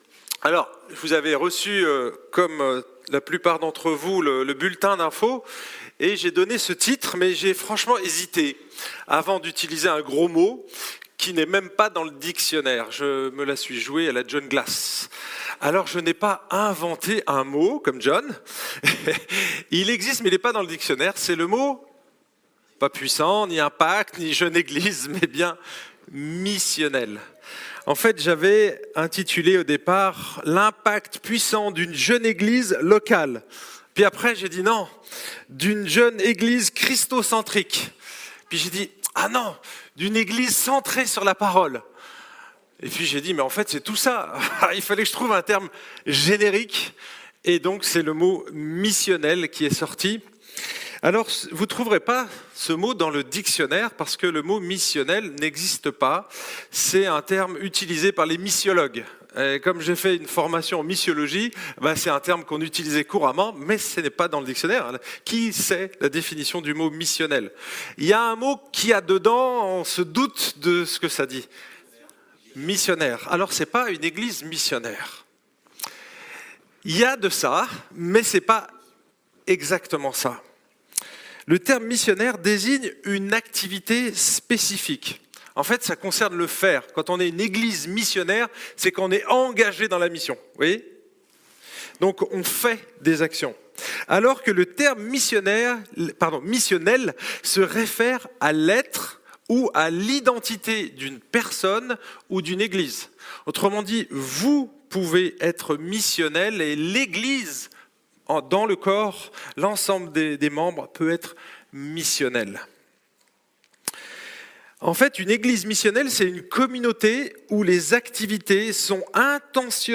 Sermons Podcasts